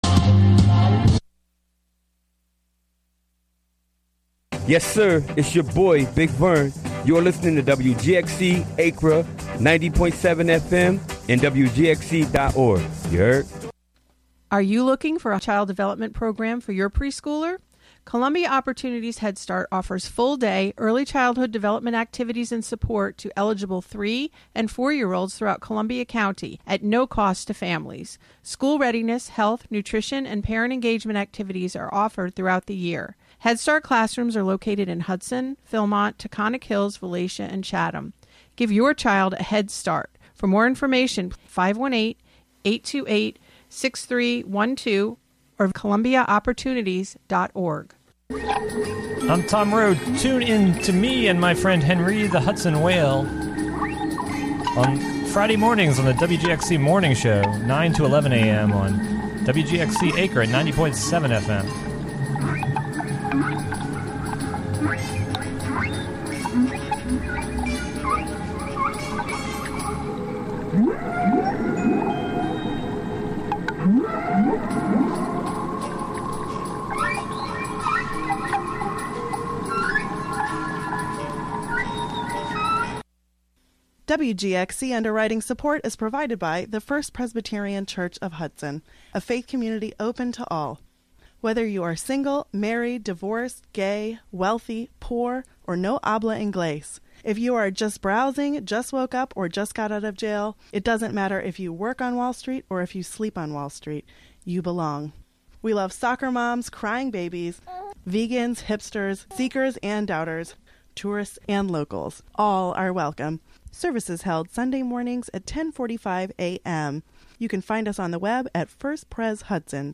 A bi-monthly show serving up rhythm and blues, soul, gospel and funk – the most memorable classics of yesterday, today and tomorrow. Broadcast live from the Carnegie Room of the Catskill Library.